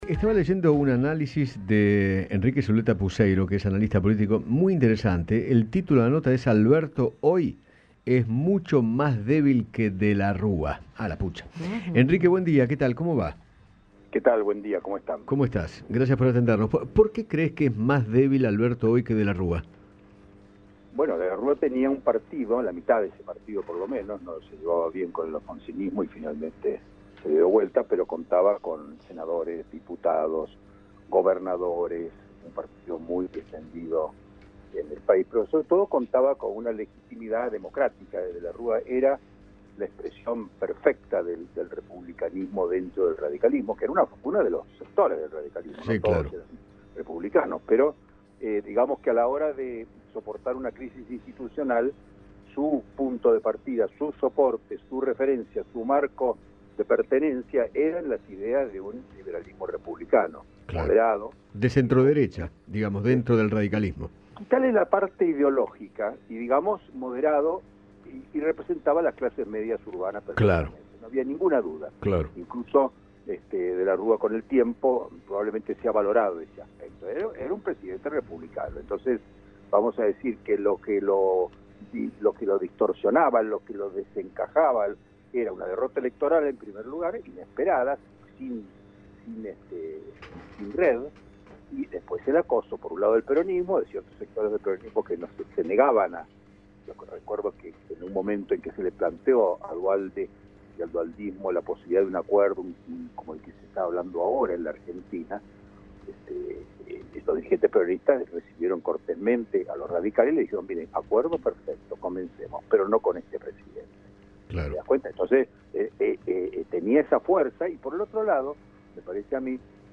Enrique Zuleta, ex gobernador de La Rioja, habló con Eduardo Feinmann sobre el poder de Alberto Fernández, luego del resultado de las elecciones legislativas, y lo comparó con el ex presidente De la Rúa.